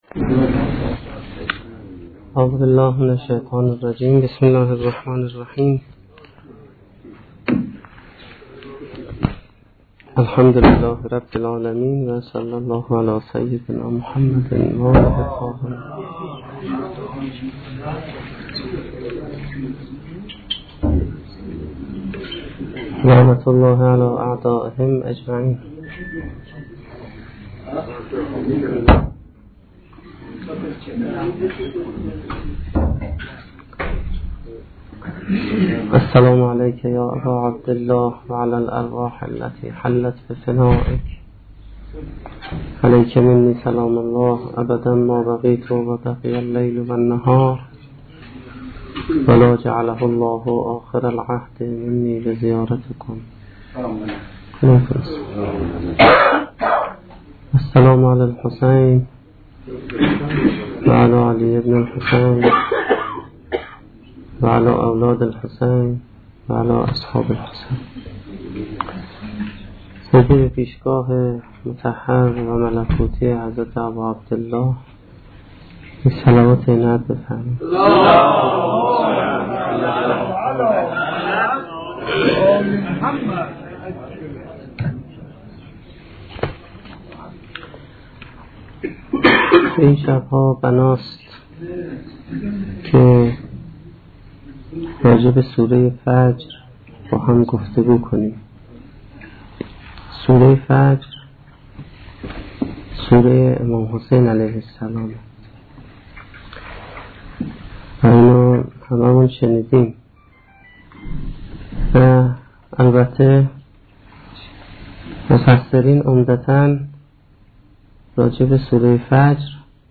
سخنرانی اولین شب دهه محرم1435-1392